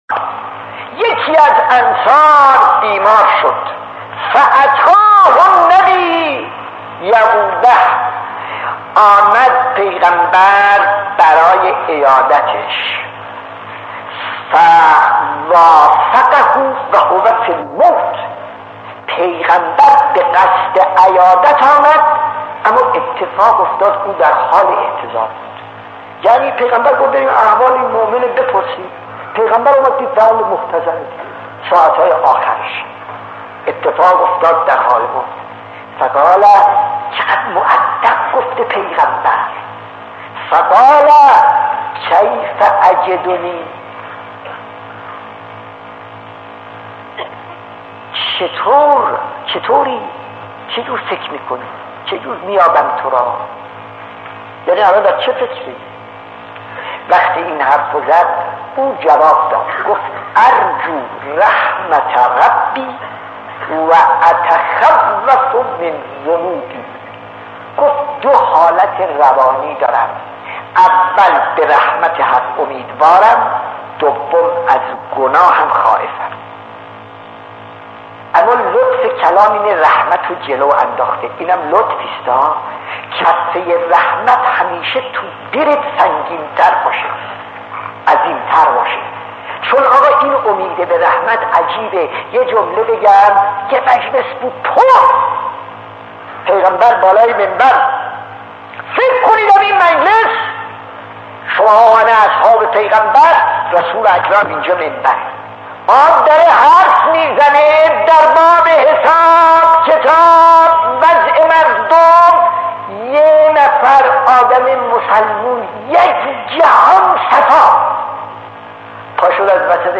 داستان 38 : پیامبر و صحابی محتضر خطیب: استاد فلسفی مدت زمان: 00:06:09